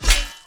melee-hit-10.mp3